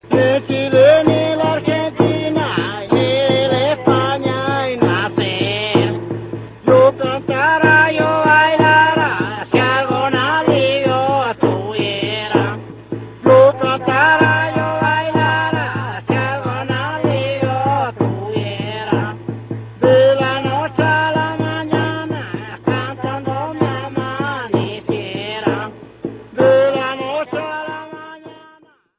CAJA CRIOLLA
Tambor de marco
Membranófono, de golpe, directo.
Marco de madera con membranas de cuero de panza de res sujetos entre sí con ataduras de cuerda de algodón en forma de V. El parche inferior tiene una chirlera, o cuerda bordona, de crin de caballo.
Acompaña el canto de coplas y el baile de ronda durante el carnaval.
Característica: La caja es el único instrumento que acompaña el canto de las coplas de carnaval en la región del desierto.
Procedencia, año: Talabre, Provincia El Loa, II Región, Chile, 1977
caja_criolla.ra